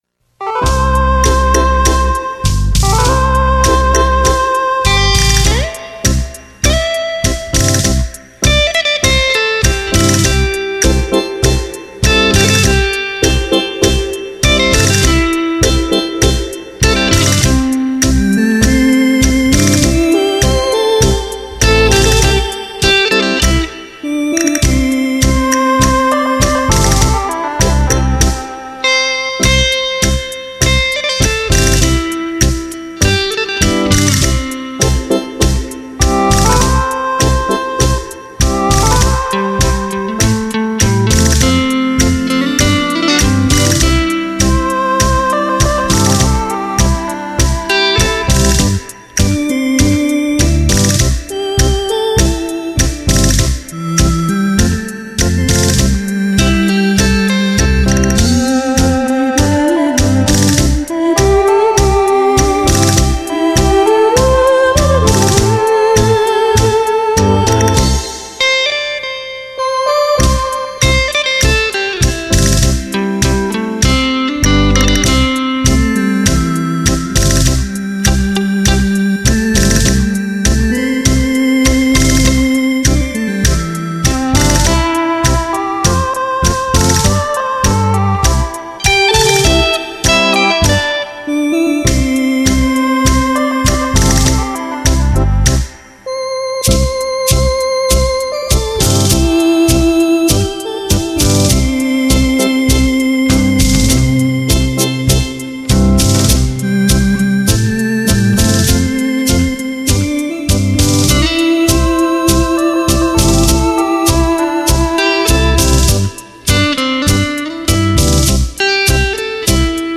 全新HQCD高清高密度24BIT发烧灌录
德国真空管录音技术
那悠扬清亮的音色，无不是心灵渴望放松休憩的一种恰到好处的绝好享受！